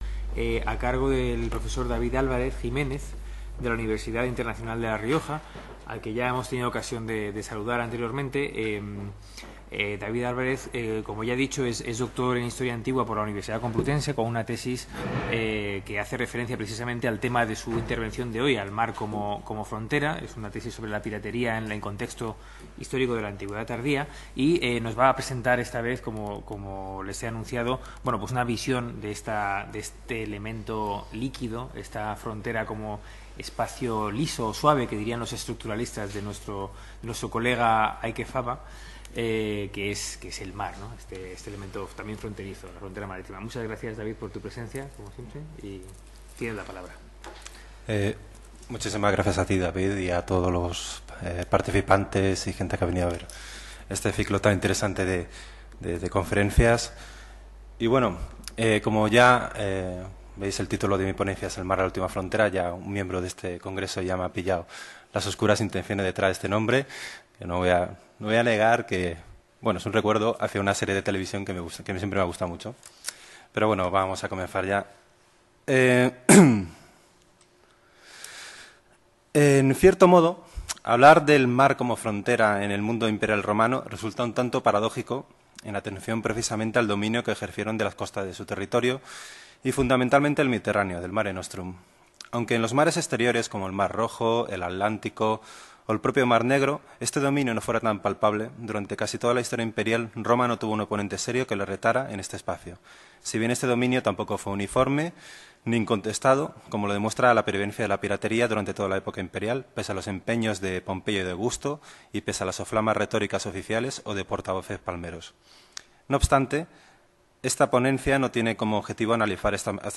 Esta II jornada de estudios sobre la frontera propone una serie de contribuciones con estudios de casos sobre el concepto de frontera en el mundo antiguo y medieval. Los conferenciantes ofrecerán una aproximación a las fuentes comparadas sobre el concepto de límite desde la época griega clásica hasta el Imperio Romano de Oriente, en los comienzos del medievo bizantino, y la Baja Edad Media.